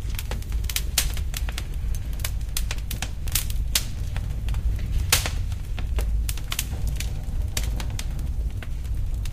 fire_loop.ogg